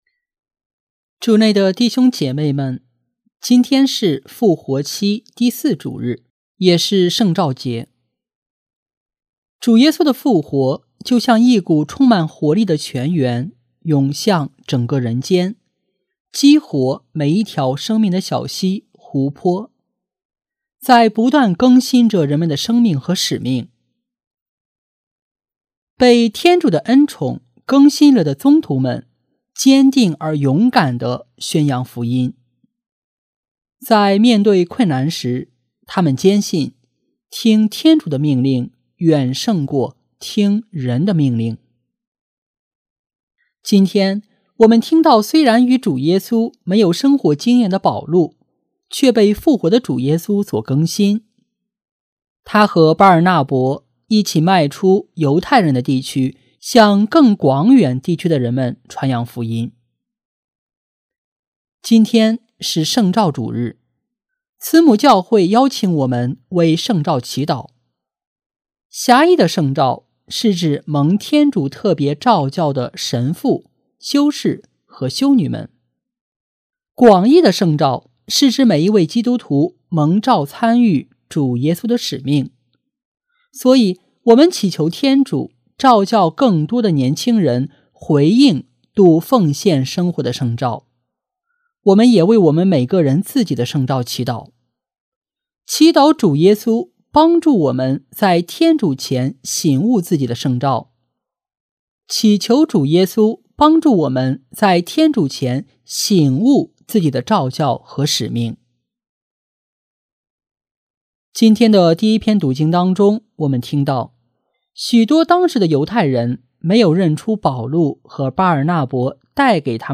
【主日证道】| 默观的生活（圣召节）